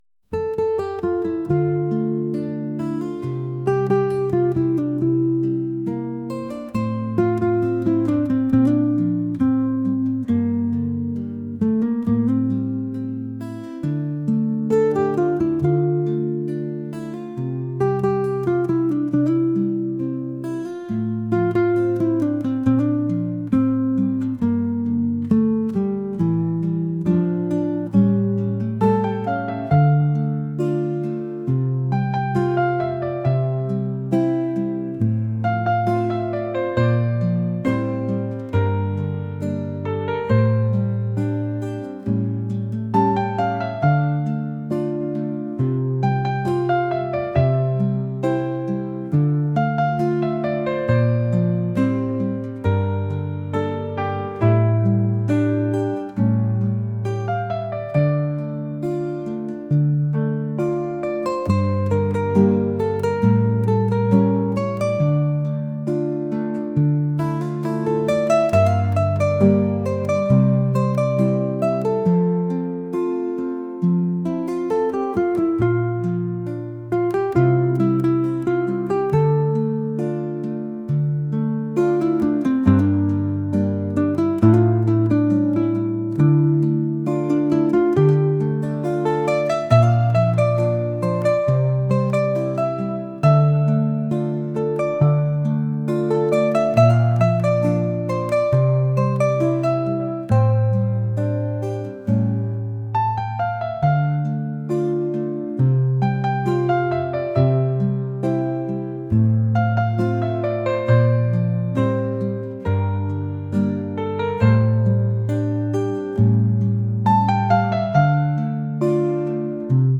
acoustic | pop | folk